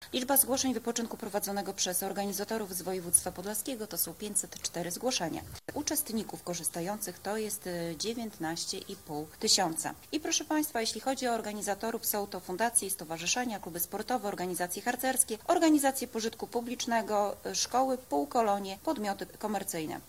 Do kuratorium wpłynęło ponad 500 zgłoszeń od organizatorów wypoczynków w regionie z czego ok. 20% zostanie skontrolowanych – przekazała Podlaska Kurator Oświaty Agnieszka Krokos-Janczyło.